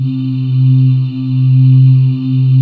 Index of /90_sSampleCDs/USB Soundscan vol.28 - Choir Acoustic & Synth [AKAI] 1CD/Partition C/05-ANGEAILES